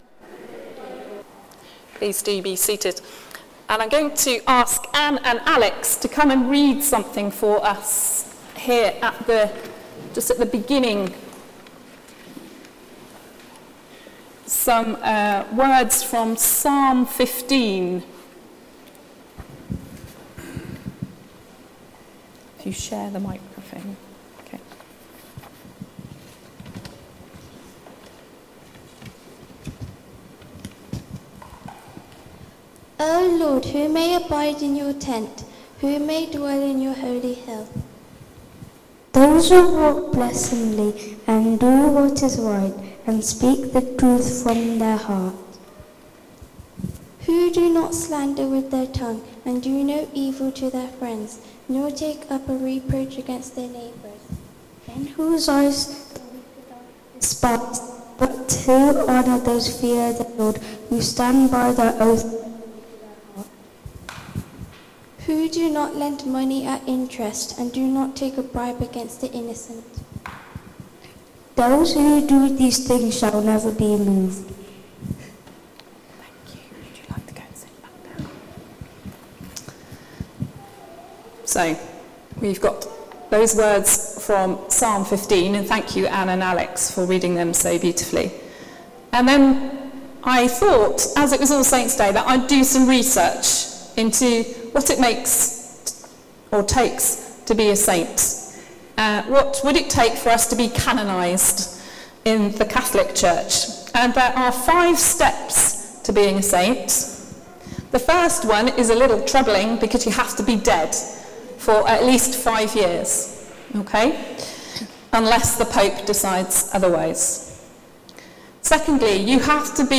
Sermon: The mark of a saint | St Paul + St Stephen Gloucester